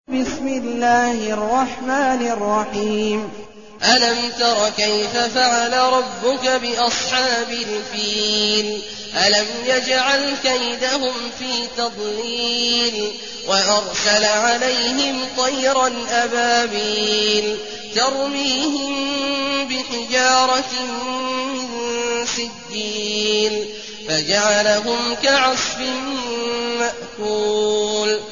المكان: المسجد النبوي الشيخ: فضيلة الشيخ عبدالله الجهني فضيلة الشيخ عبدالله الجهني الفيل